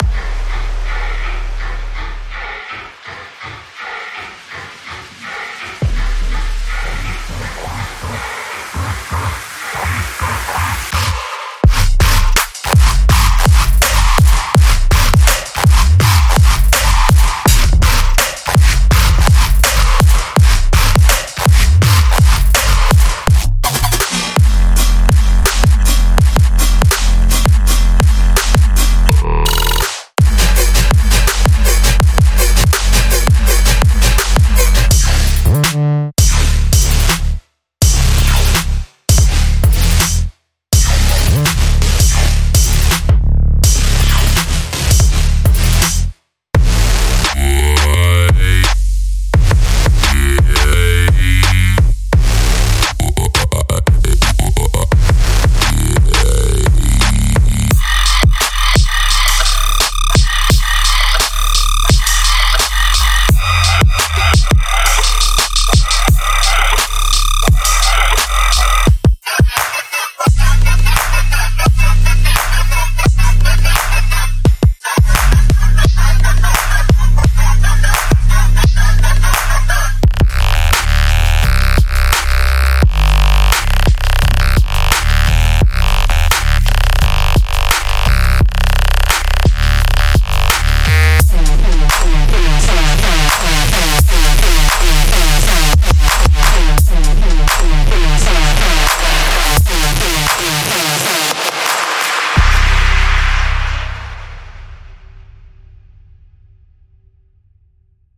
trap music and bass music
high-octane collection cutting-edge one-shots and loops